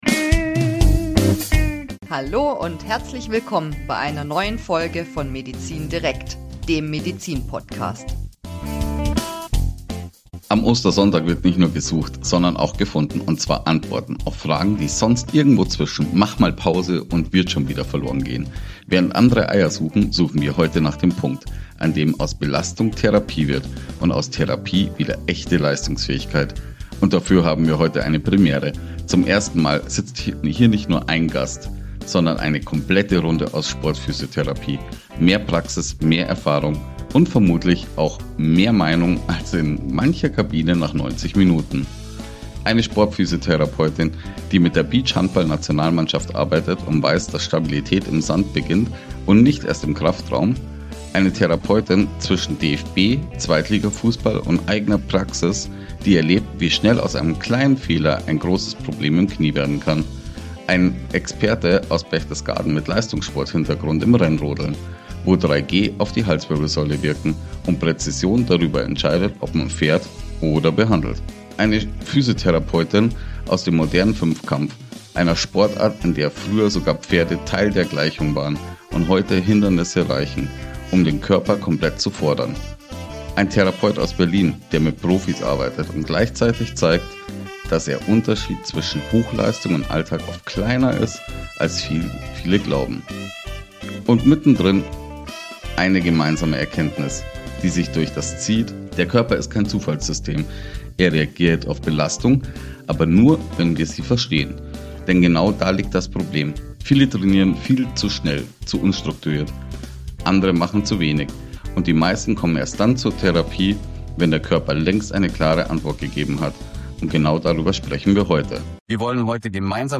Was moderne Sportphysiotherapie heute wirklich bedeutet – zwischen Therapie, Training und Belastung. Eine besondere Folge, aufgenommen im Rahmen eines DOSB Sportphysiotherapie-Kurses, mit Einblicken aus Praxis, Leistungssport und Alltag.